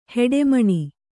♪ heḍe maṇi